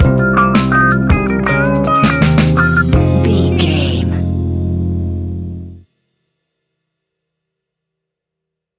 Fast paced
In-crescendo
Uplifting
Ionian/Major
F♯
hip hop